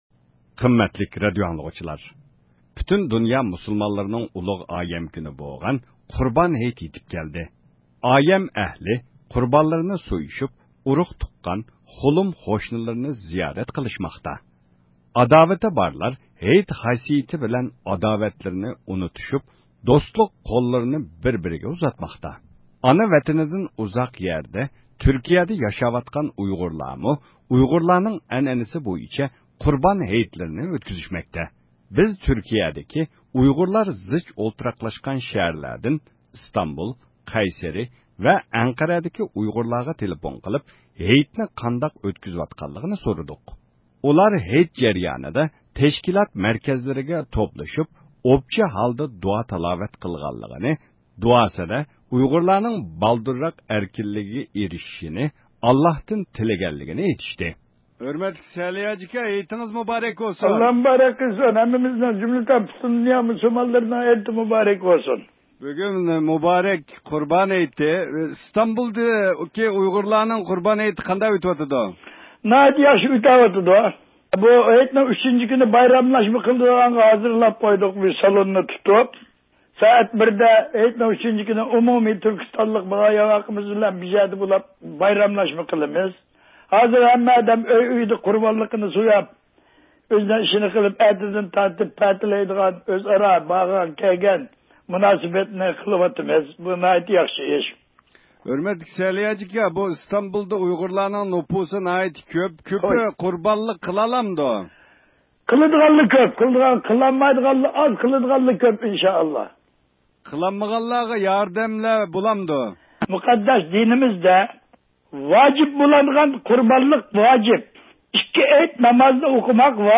بىز تۈركىيىدىكى ئۇيغۇرلار زىچ ئولتۇراقلاشقان شەھەرلەردىن ئىستانبۇل، قەيسىرى ۋە ئەنقەرەدىكى ئۇيغۇرلارغا تېلېفون قىلىپ ھېيتنى قانداق ئۆتكۈزۈۋاتقانلىقىنى سورىدۇق.